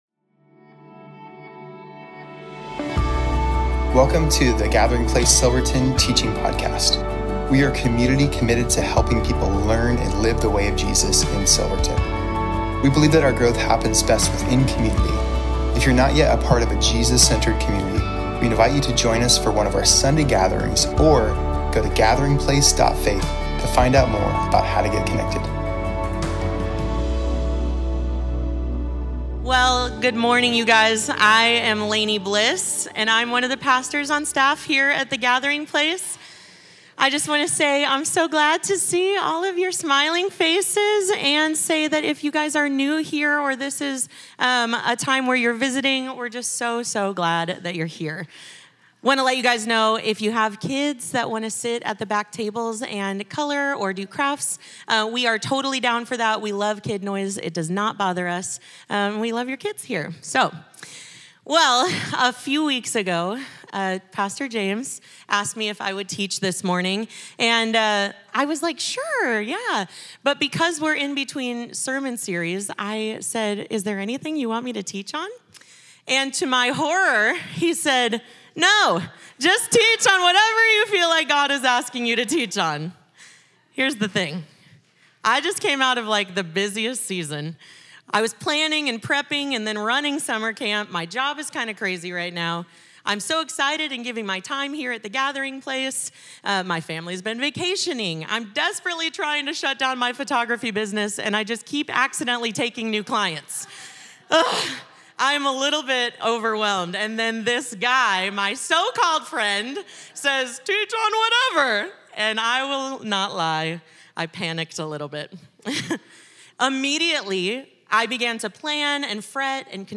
Home About Connect Events Sermons Give The Lord is My Shepherd-Stand Alone Message August 24, 2025